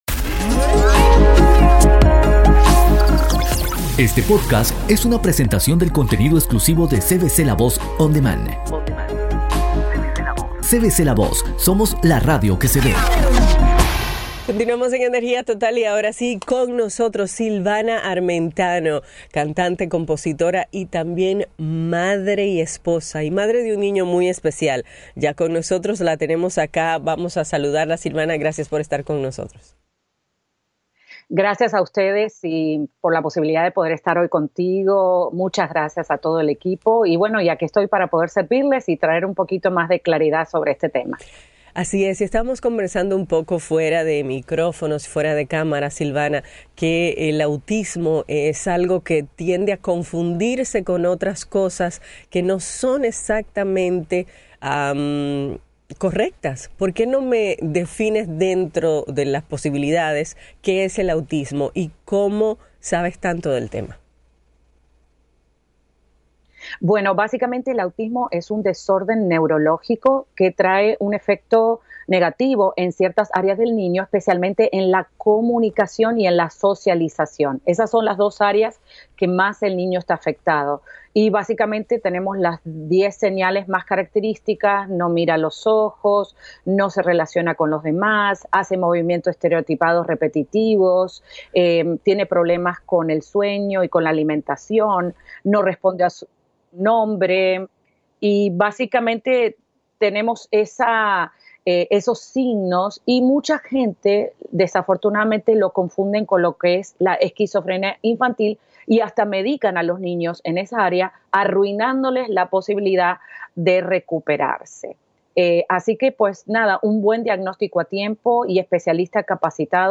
En este libro y durante la entrevista ella explica lo que es el autismo, cuales son algunas de sus características principales y algunas terapias muy efectivas.